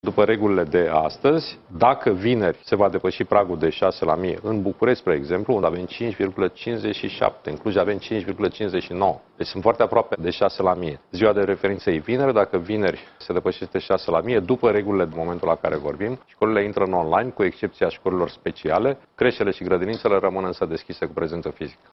Ministrul Sorin Cîmpeanu, la Digi 24.